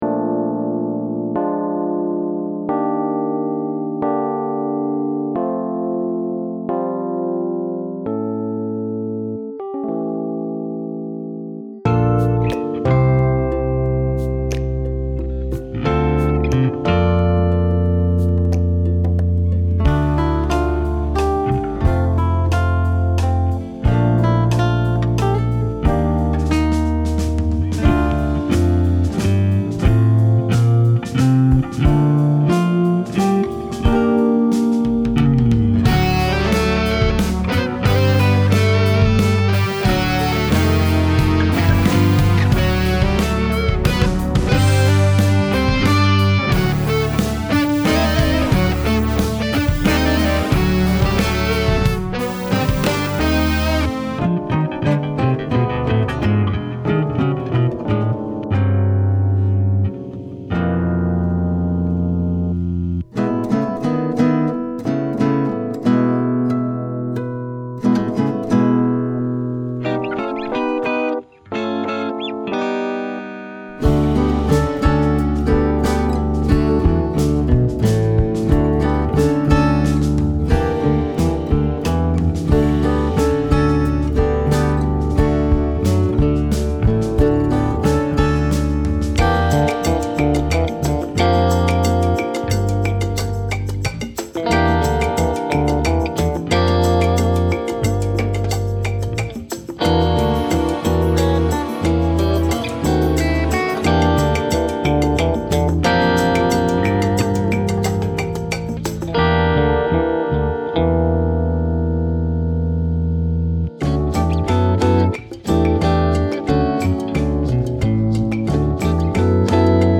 Een stuk voor vier hoorns Fundamenteel onderzoek naar het waterstof molecuul draagt bij aan betere zonnepanelen.